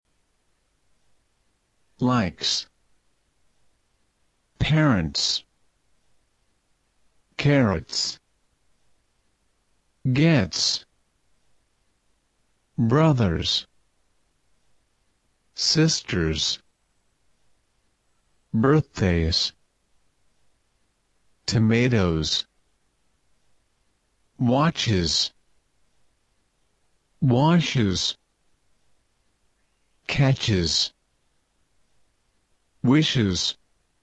Pronouncing S